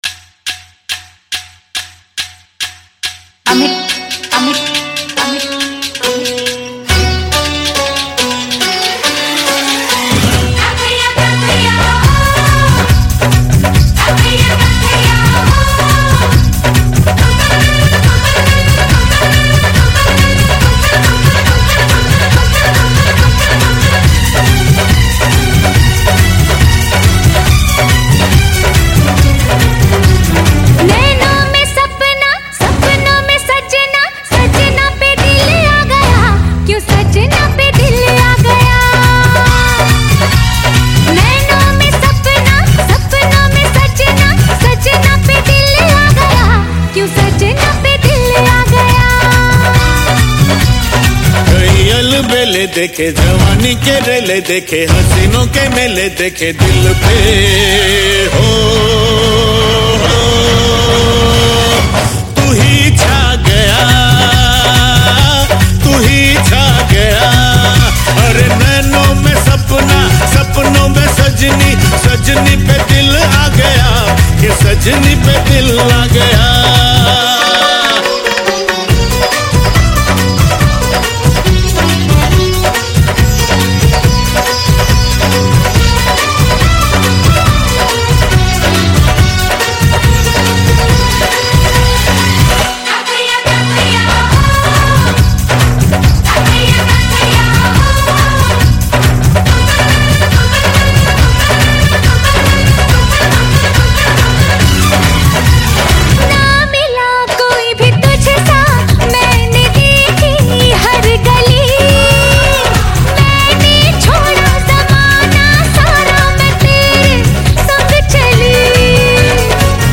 Filters Dj Remix Songs ,